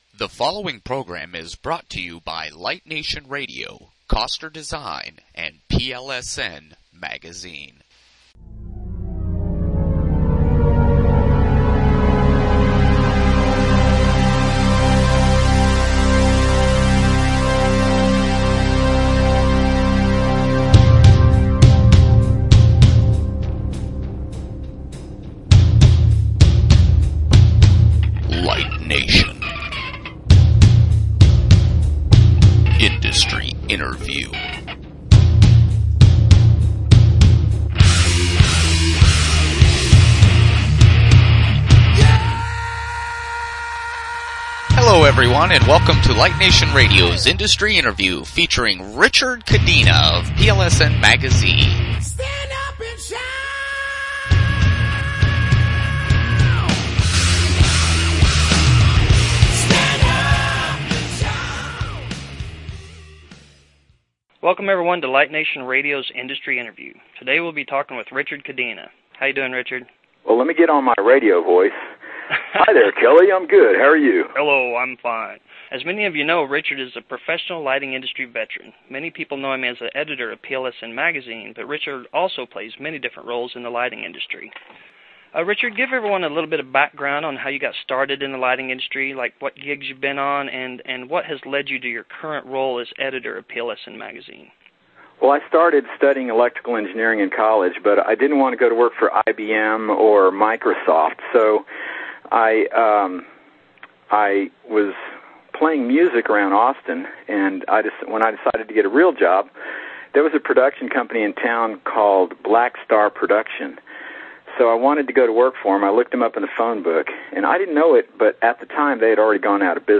2008 This is a two-part interview as shown below.